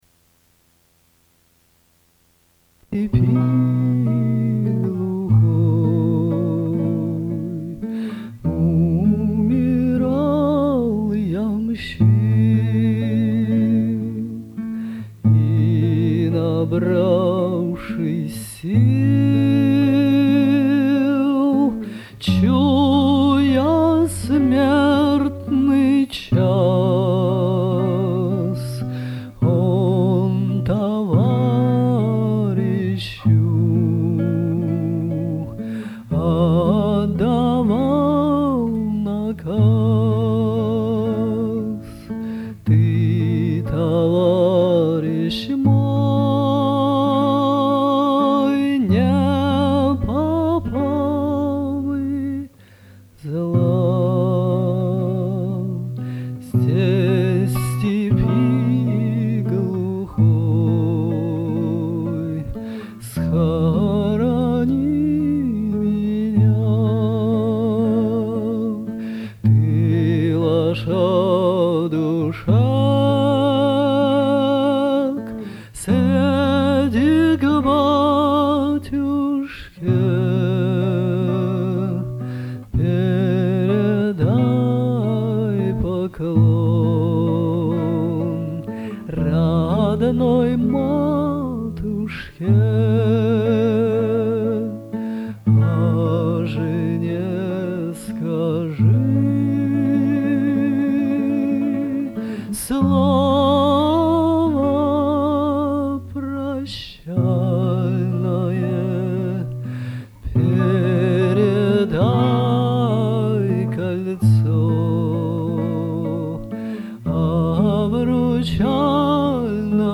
песни и романсы
Это один из самых интересных теноров у нас.